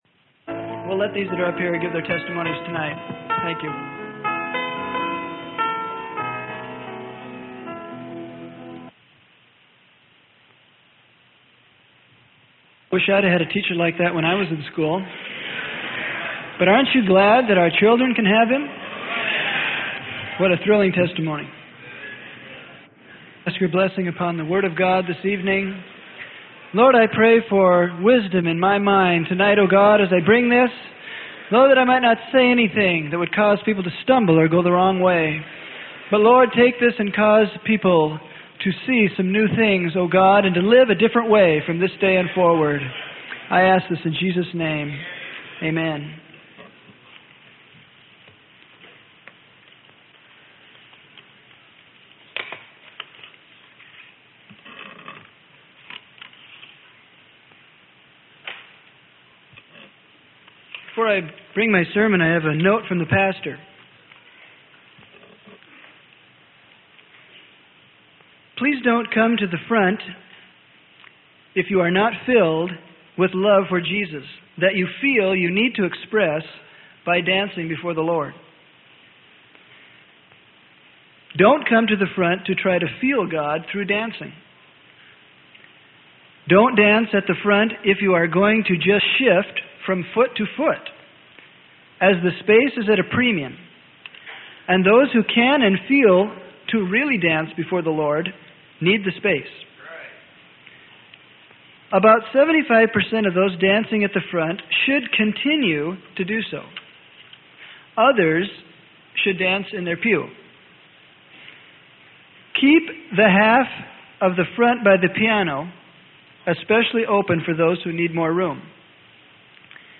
Sermon: How Or What Do You See?